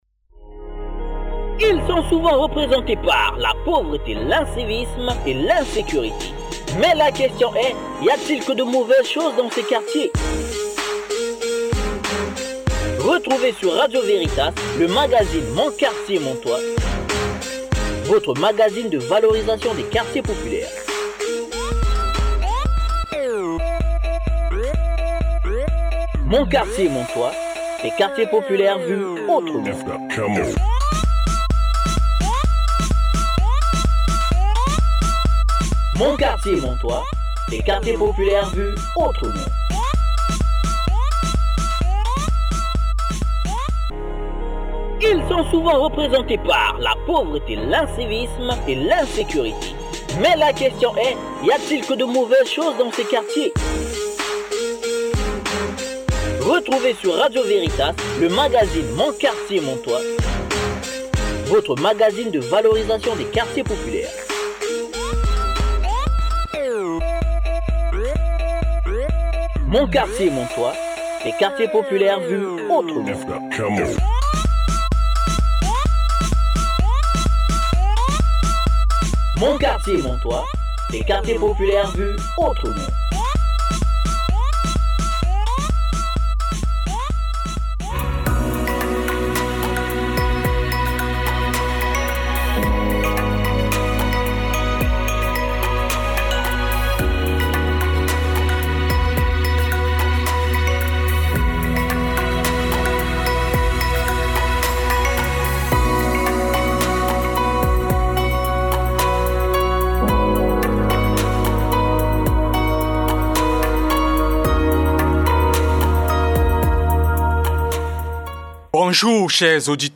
Pour ceux et celles qui ont ratés la diffusion de la première édition de l’émission « Mon quartier, mon toit » produit par le Projet AQP et diffusée sur Radio Véritas le 17 mars 2021, vous pouvez vous rattraper en cliquant le lien ci-dessous. Pour rappel, cette première édition était entièrement consacrée à la présentation du Projet AQP et du CODAS Caritas Douala qui en est l’agence de mise en oeuvre.